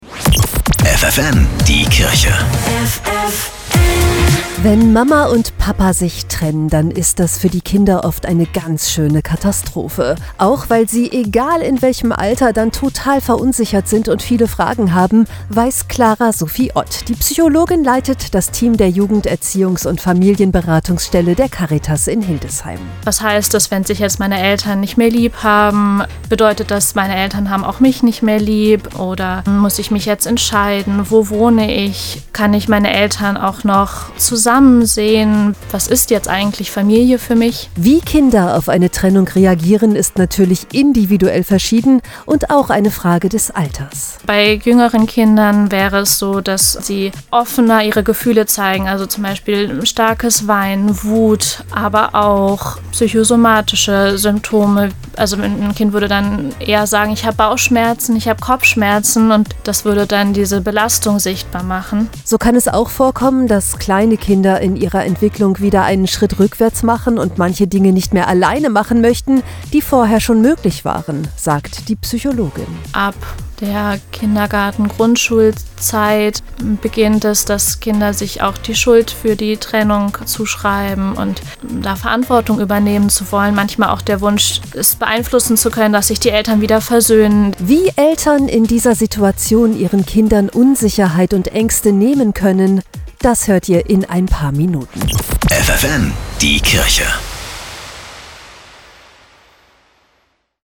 Radiobeiträge: